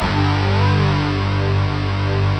Index of /90_sSampleCDs/Optical Media International - Sonic Images Library/SI1_DistortGuitr/SI1_200 GTR`s